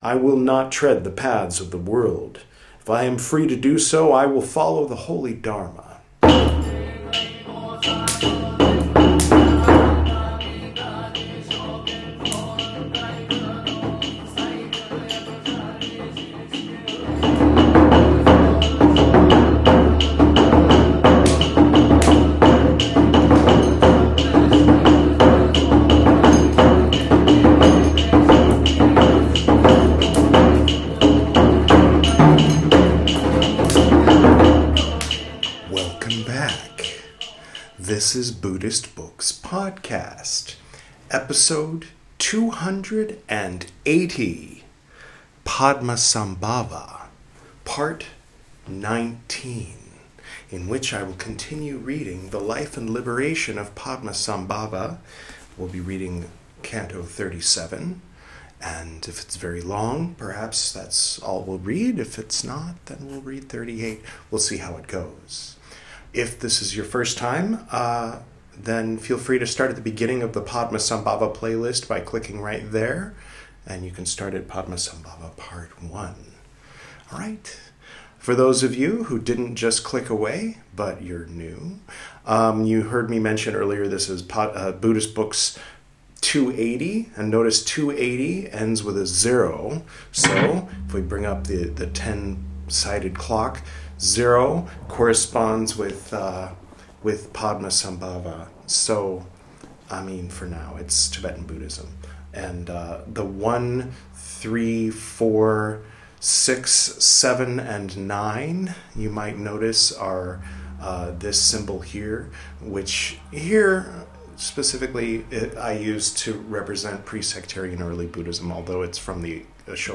This is Part 19 of my recital of the 'The Life & Liberation of Padmasambhava,' a translation of 'Padma Thang-yig,' by Yeshe Tsogyal. In this episode, we'll read Cantos 37, 38 & 39.